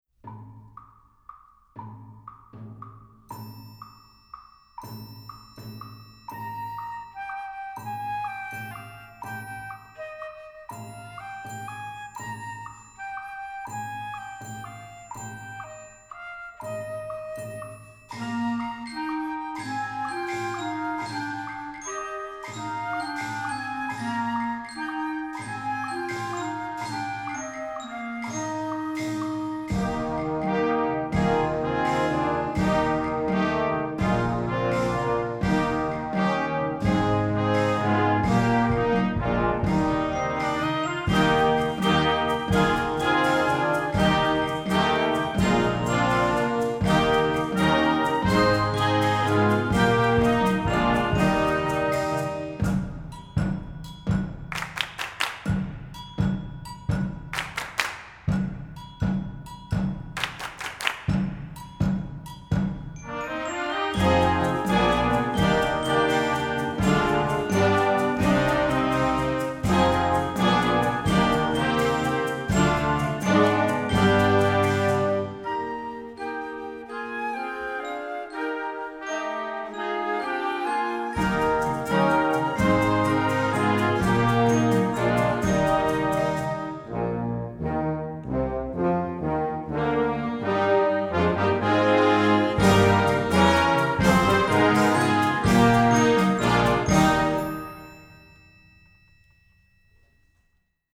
Voicing: Full Score